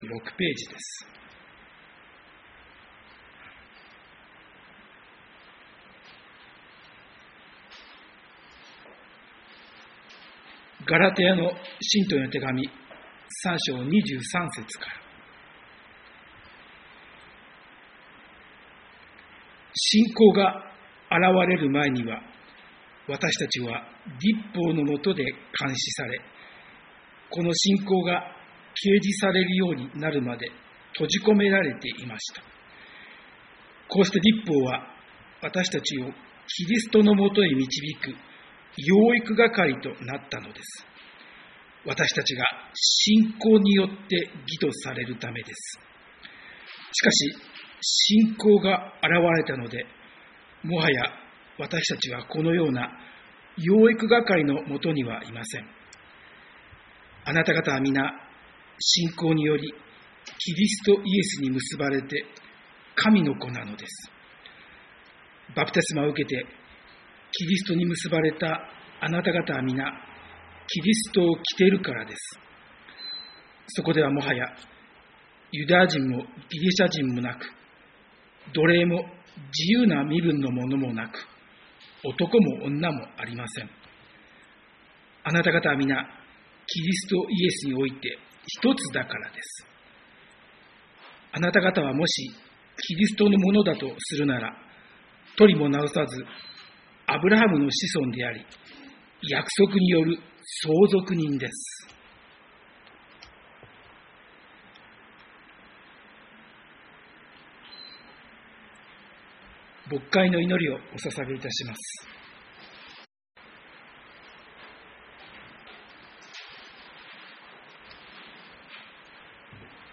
日曜 朝の礼拝